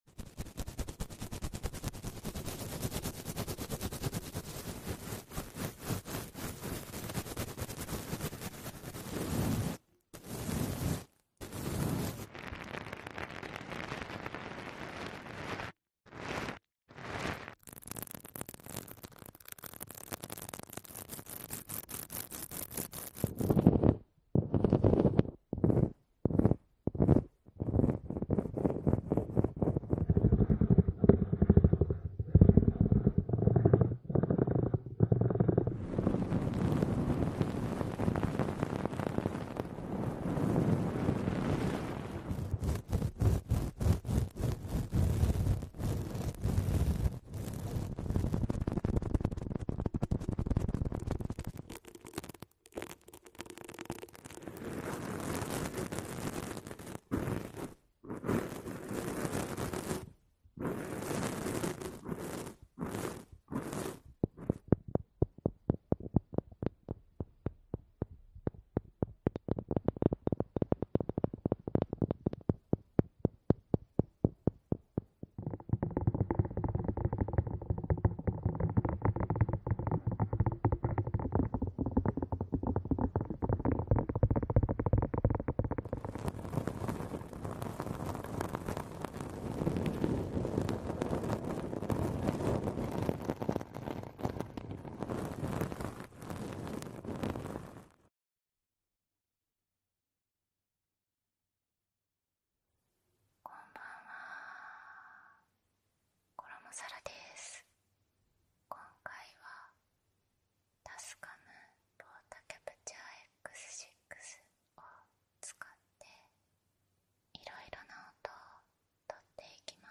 Power ASMR Triggers for Deep Sleep ⚡😴
This episode features intense and carefully crafted sounds designed to help you unwind, melt away stress, and drift effortlessly into a deep, restorative sleep.
Plug in your headphones and let the soothing triggers take you on a peaceful journey to dreamland.